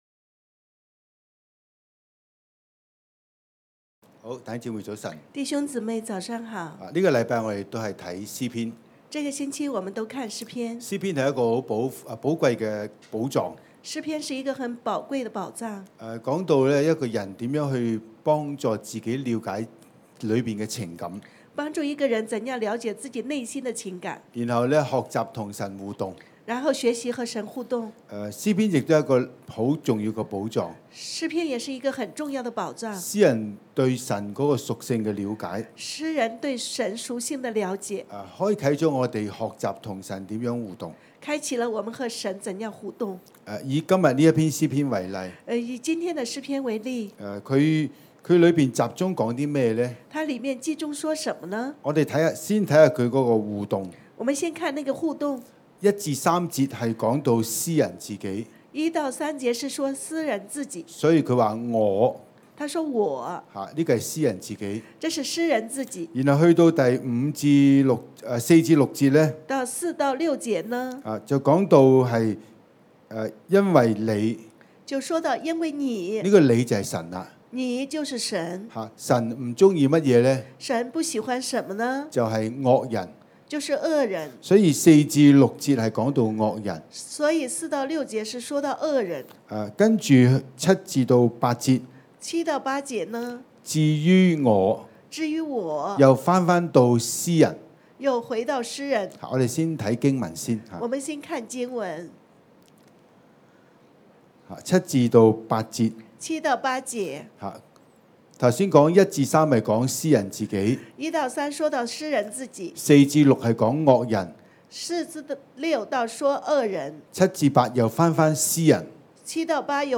方言禱告，全心讚美敬拜神。